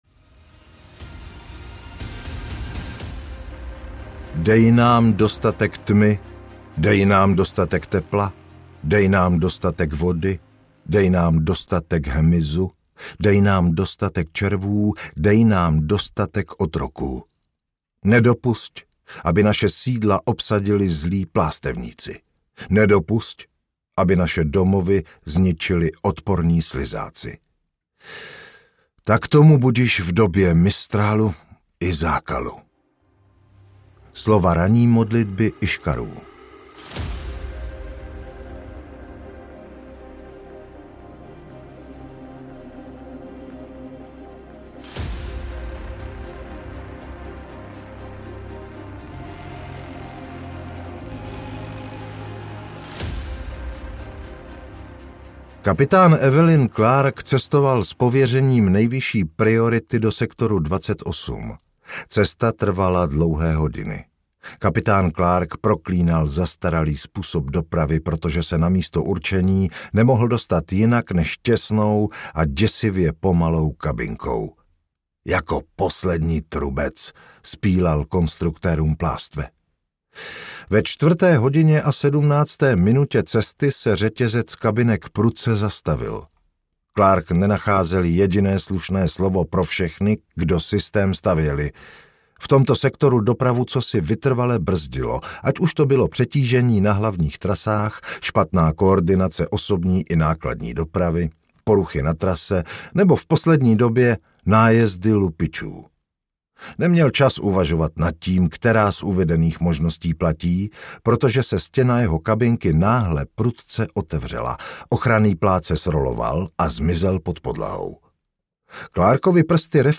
Děti plástve – kniha první audiokniha
Ukázka z knihy